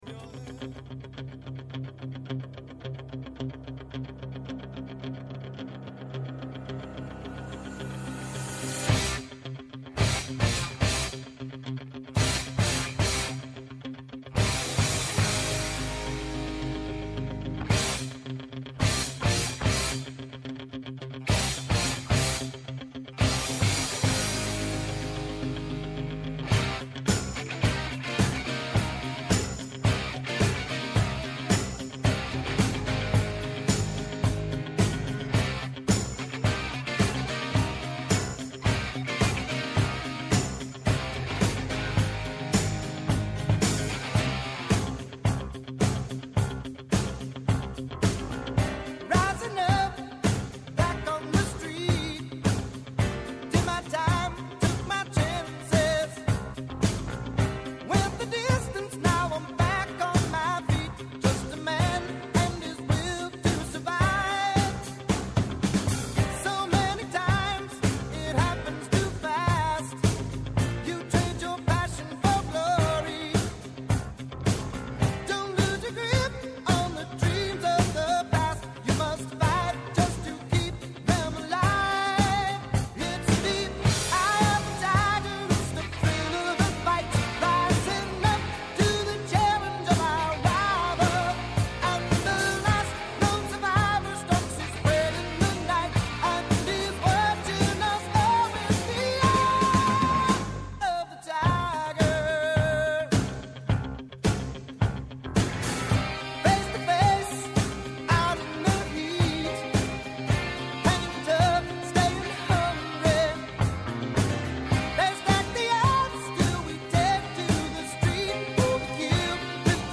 Ρεπορτάζ, συνεντεύξεις και πλούσιο παρασκήνιο αποτελούν τα βασικά συστατικά της εκπομπής.